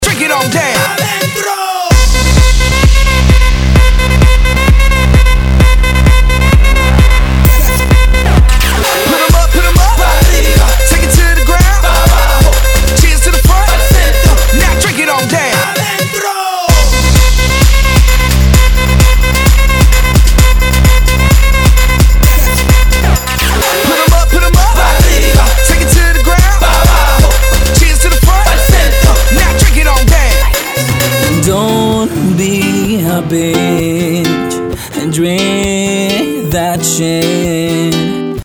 • Качество: 192, Stereo
Подвижгый и мощный бит